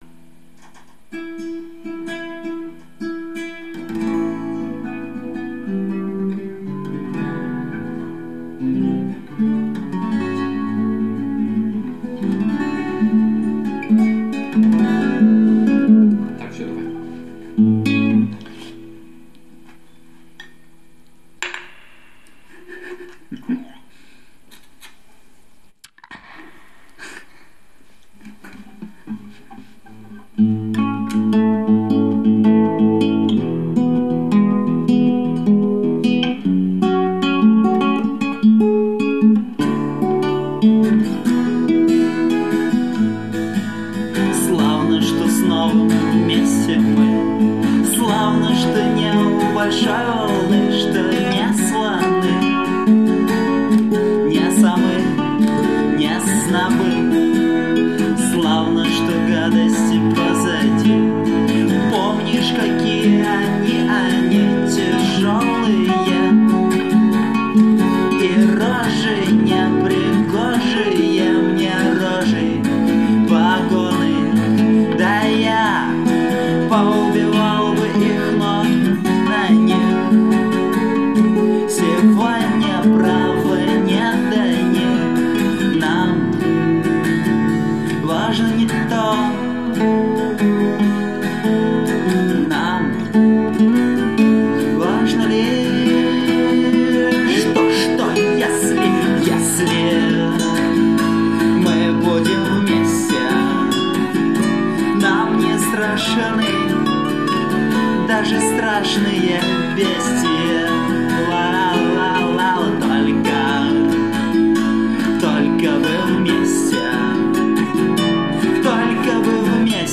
• Жанр: Рок